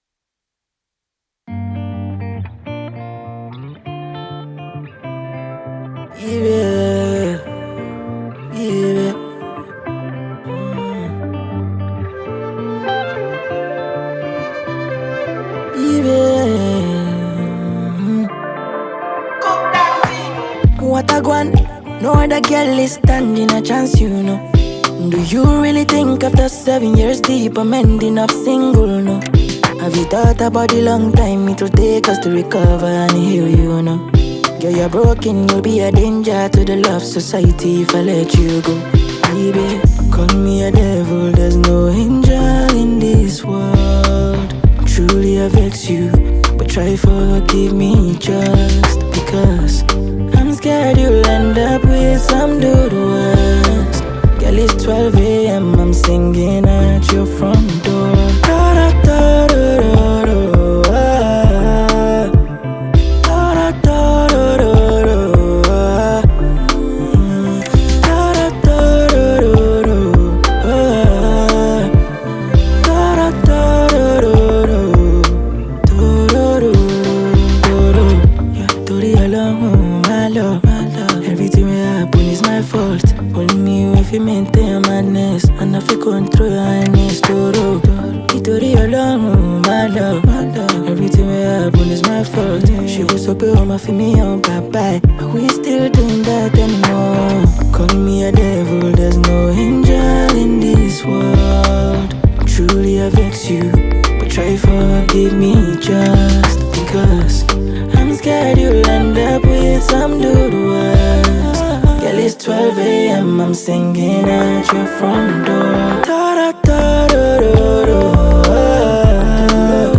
vibrant Afro-Dancehall track
blends catchy rhythms
smooth vocals